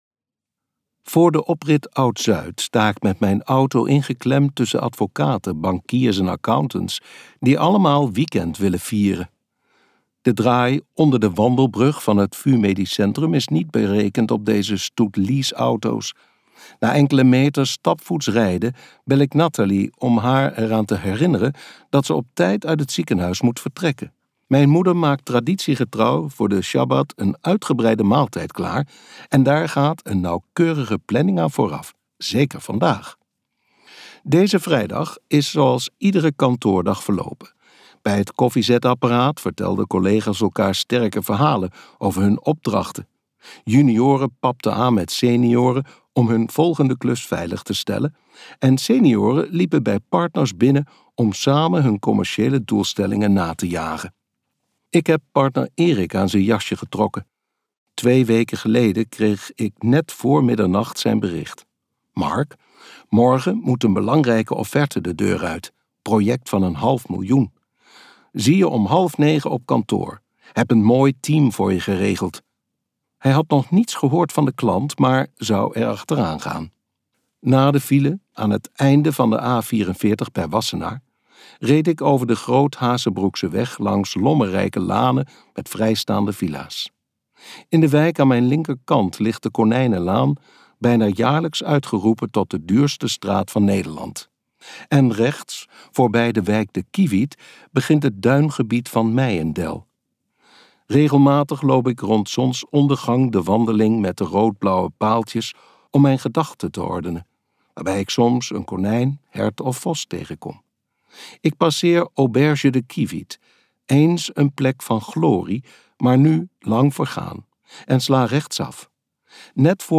Ambo|Anthos uitgevers - Het verleden is voor later luisterboek